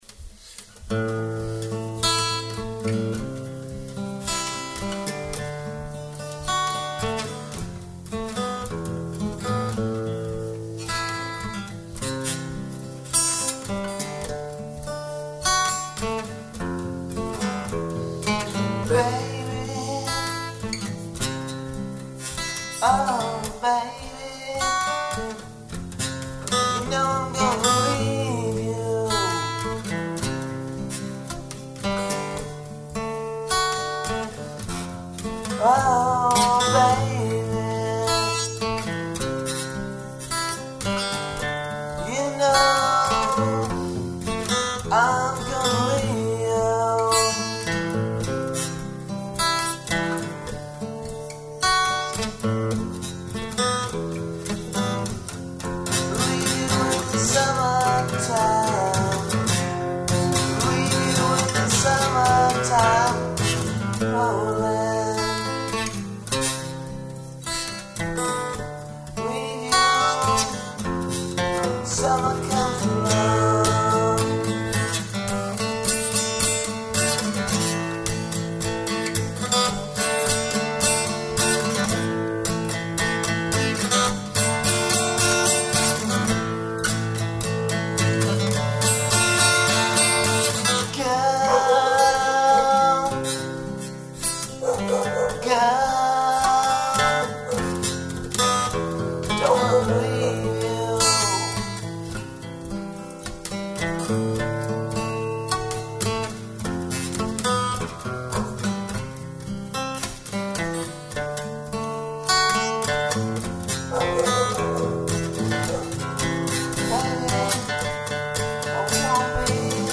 vocals and guitar